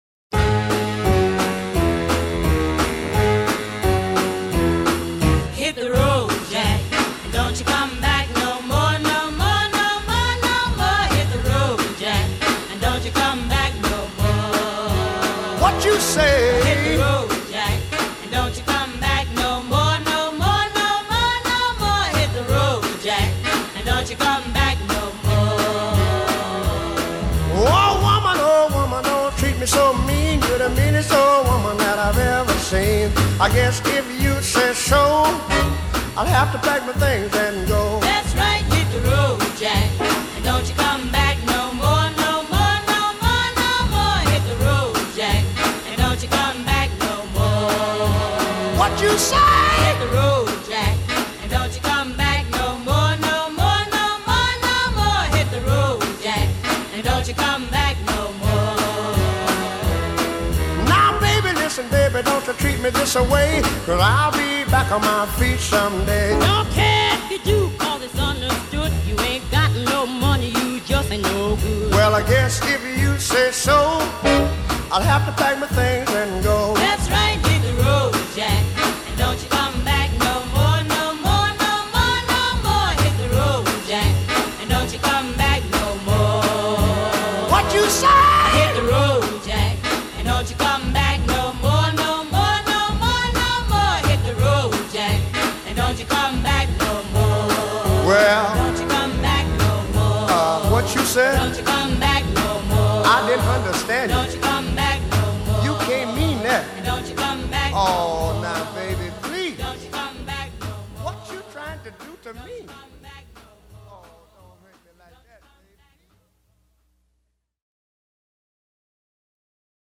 Side A (Soul):
Soul, Jazz, Blues, Country, Pop